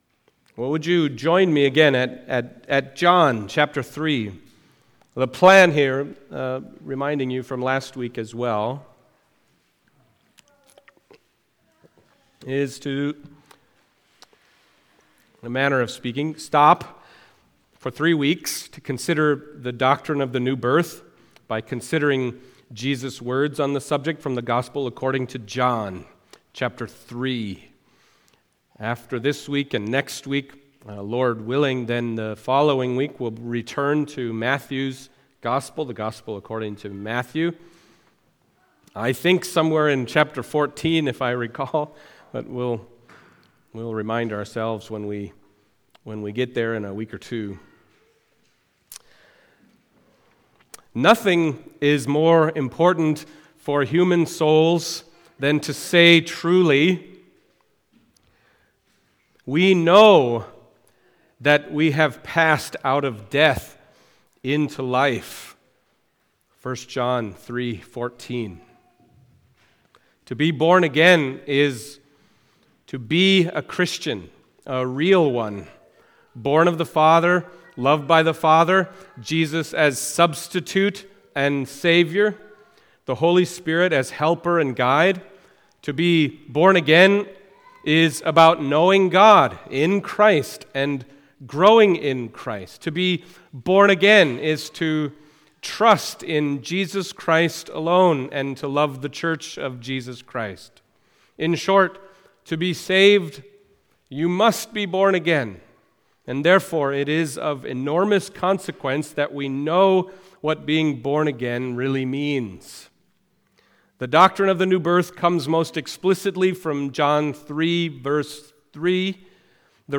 John 3:1-10 Service Type: Sunday Morning John 3:1-10 « You Must Be Born Again